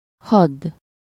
Ääntäminen
US : IPA : [lɛt]